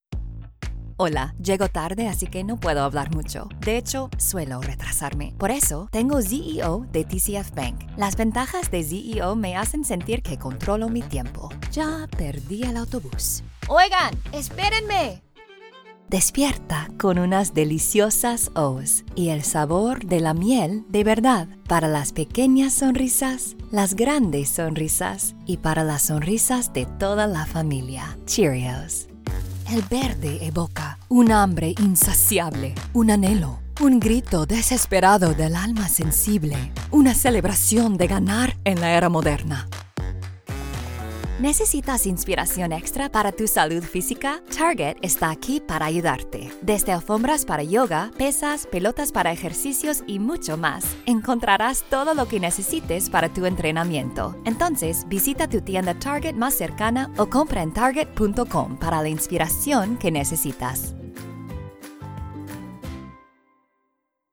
Trilingual VO pro with a warm, personable, sunny, trustworthy, friendly, and fun vibe!
Spanish Commercial Demo
Spanish - Neutral
My voice has a warm, personable, sunny, trustworthy, friendly, fresh, and fun quality.